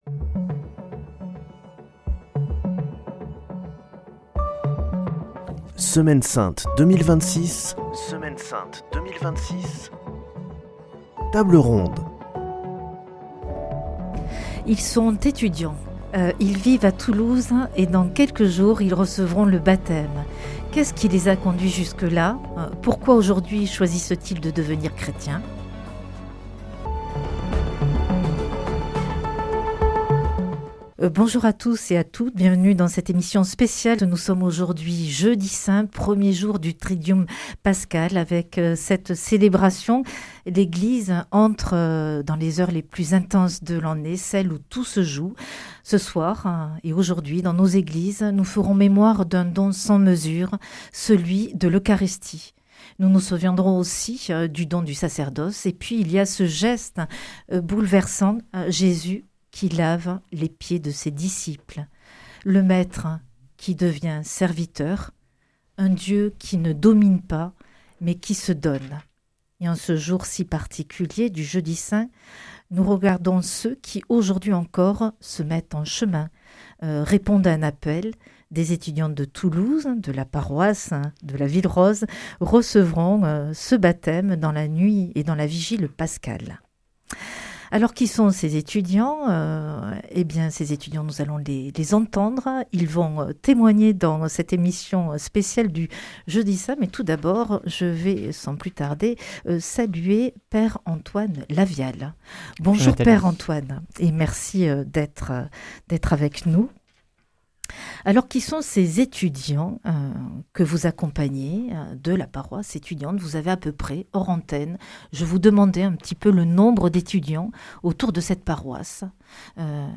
Deux étudiantes de Toulouse s'apprêtent à recevoir le baptême dans la nuit de Pâques. Avec leur curé, elles racontent leur chemin, leur quête, et ce que la foi change déjà dans leur vie. En ce Jeudi Saint, leurs témoignages font écho à un Dieu qui se donne et se fait serviteur.